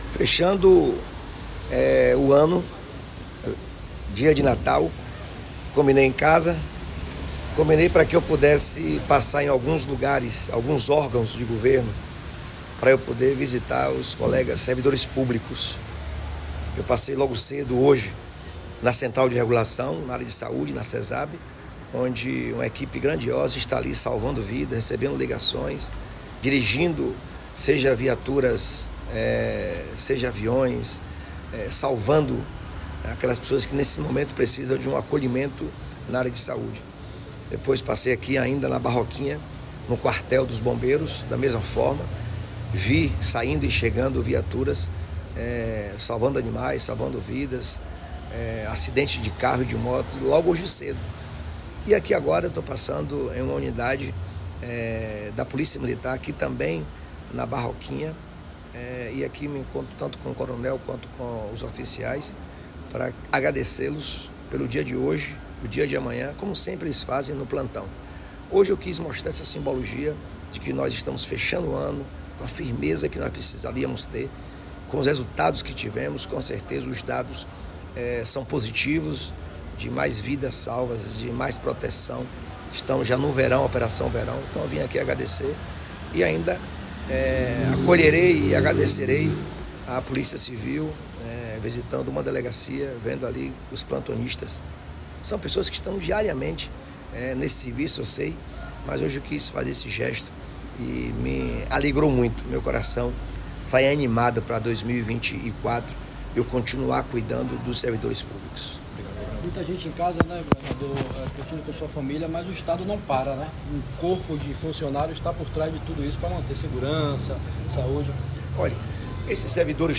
🎙Governador Jerônimo Rodrigues